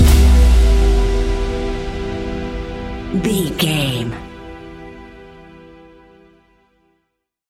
Ionian/Major
E♭
electronic
techno
trance
synths
synthwave
instrumentals